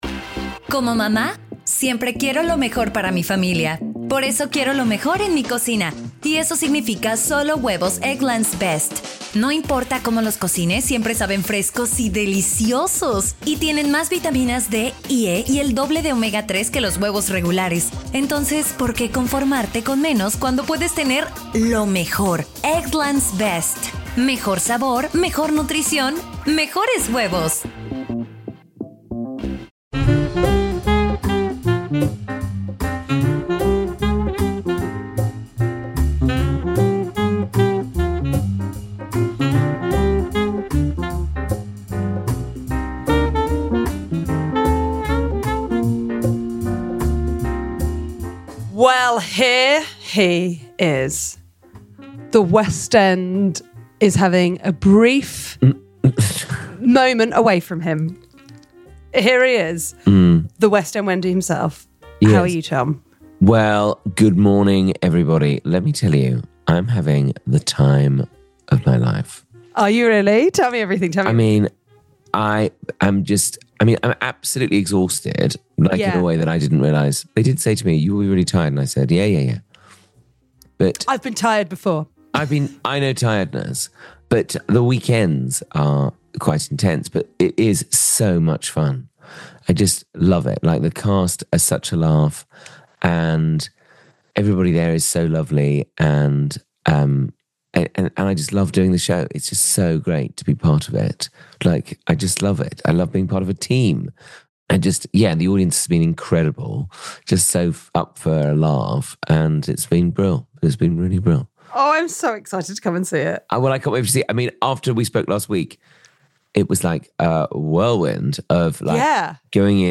Comedians and dearest pals Tom Allen and Suzi Ruffell chat friendship, love, life, and culture... sometimes...